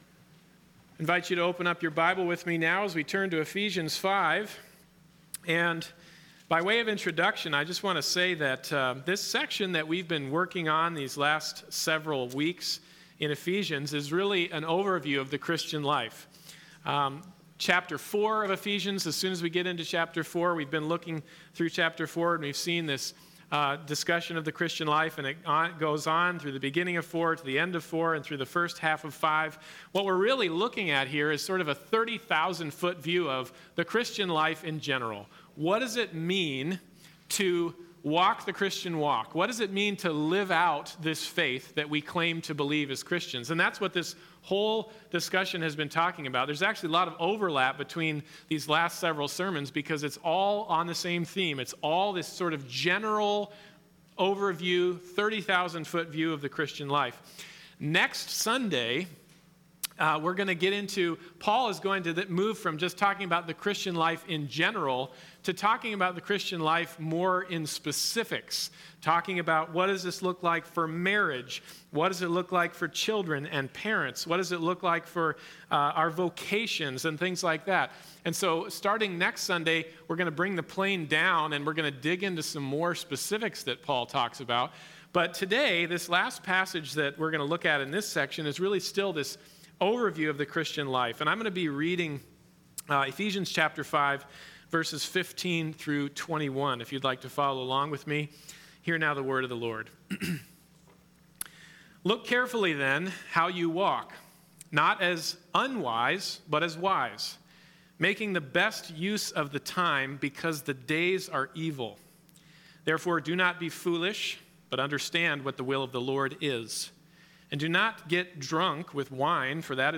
Series: The Book of Ephesians Passage: Ephesians 5:15-21 Service Type: Sunday Morning Service